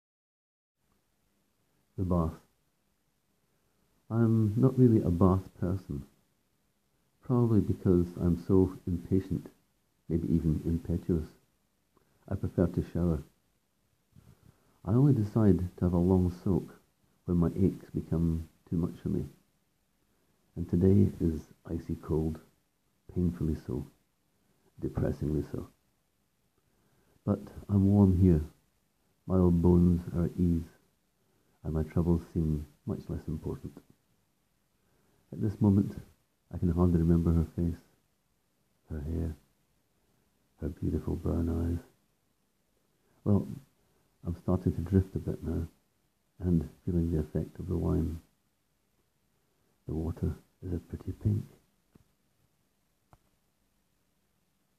Click here to hear me read this 1-minute story: